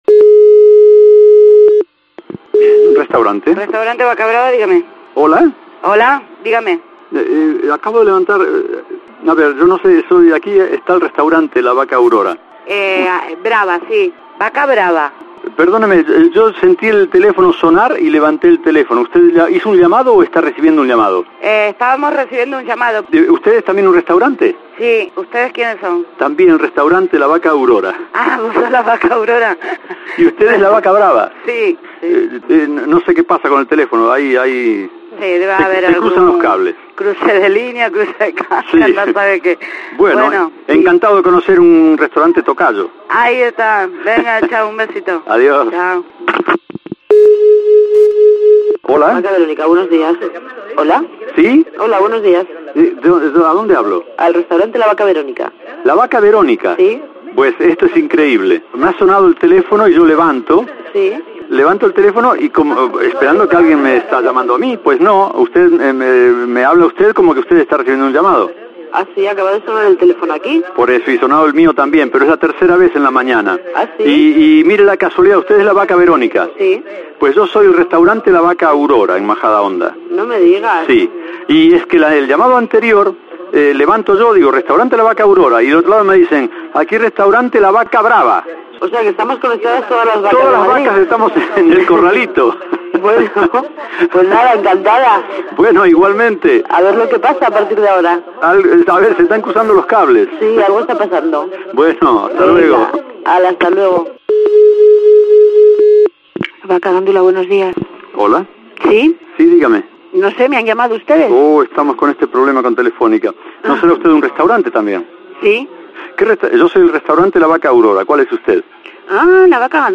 Cruzamos los teléfonos de los restaurantes "La vaca brava" y "La vaca aurora" y que la magia de la radio haga el resto
El Grupo Risa inventa esto de las llamadas cruzadas: con dos teléfonos, en uno se marca un número, en otro se marca otro, se conectan entre ellos, y como los dos suenan a la vez, cada interlocutor lo coge y dice "diga"; y nosotros los dejamos, que ya se entenderán.